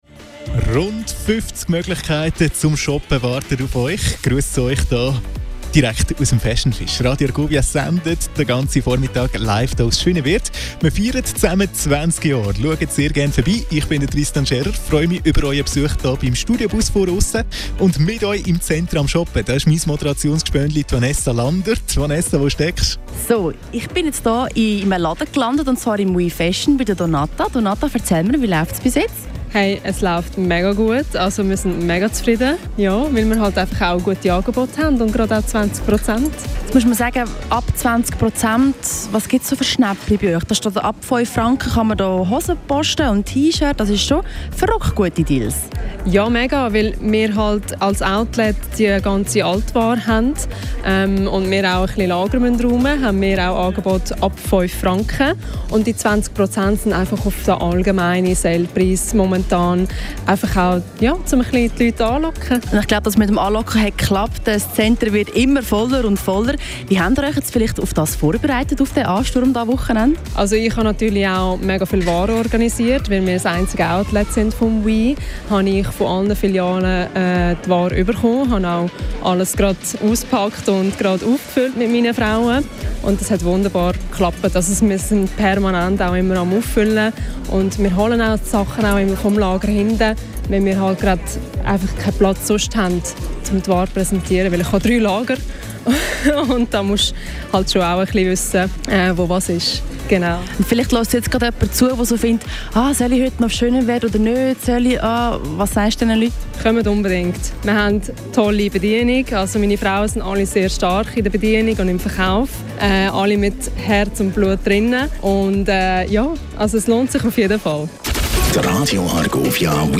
Interview mit Mitarbeitende
Radio Argovia live bei Fashion Fish 27.09.2025